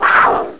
CAT_WARP.mp3